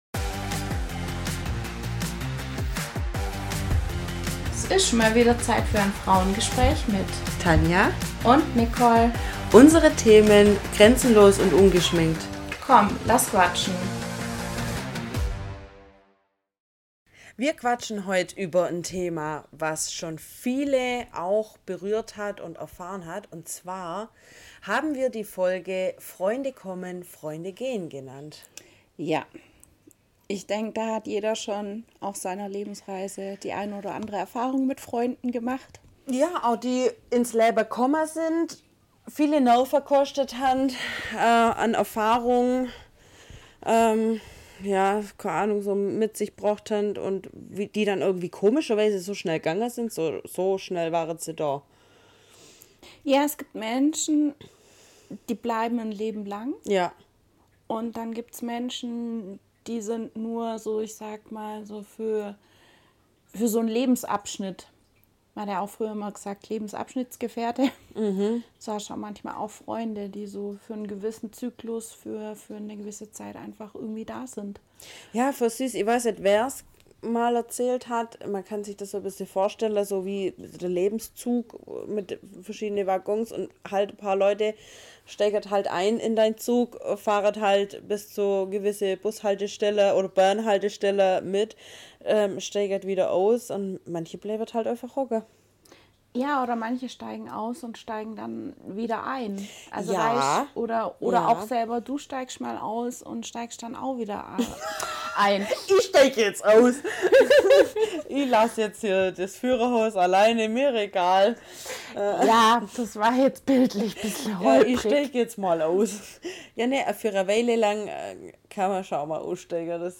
#028 Freunde kommen - Freunde gehen ~ Frauengespräche │ grenzenlos & ungeschminkt Podcast